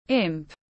Linh hồn quỷ nhỏ tiếng anh gọi là imp, phiên âm tiếng anh đọc là /ɪmp/
Imp /ɪmp/